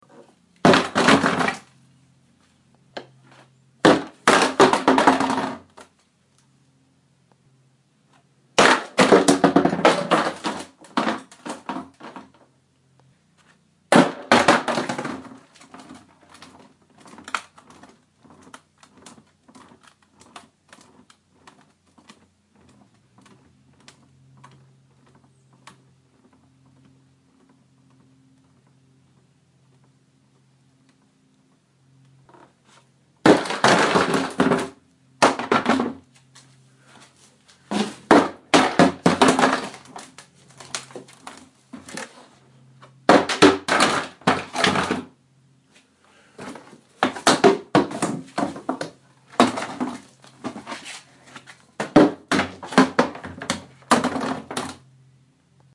将垃圾袋放进垃圾桶
描述：将塑料垃圾袋放入垃圾桶。可以听到一些回声和靴子。
标签： 垃圾桶 垃圾桶 塑料 免费 沙沙 集装箱 声音
声道立体声